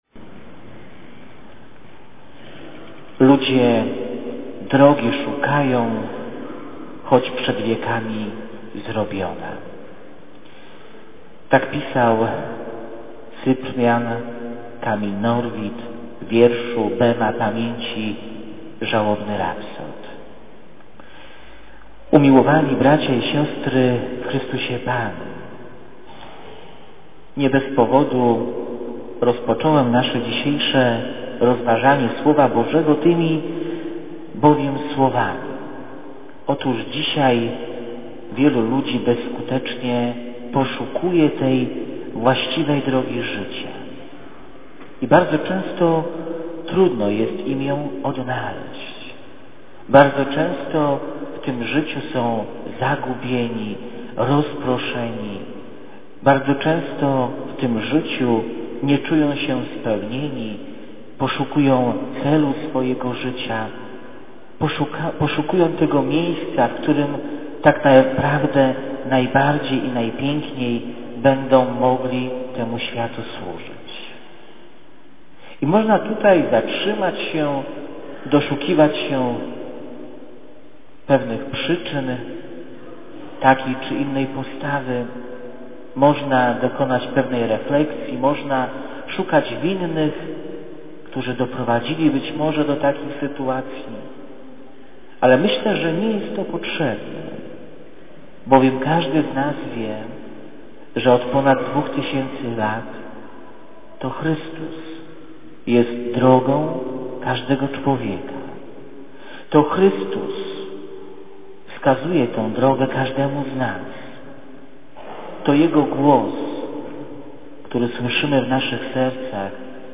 A naszym Parafianom sk�adamy serdeczne podzi�kowania za ofiary z�o�one na nasze seminarium...!!! niedzielne kazanie z tego wydarzenia .mp3